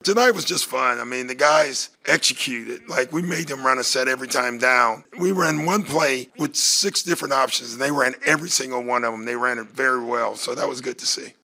Bucks coach Doc Rivers talked about the win.